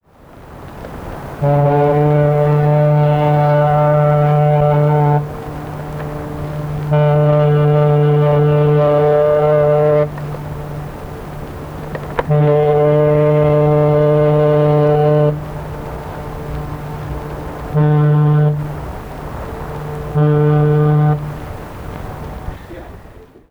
Master Salute of the Arthur M. Anderson from 1978 in Two Harbors, MN.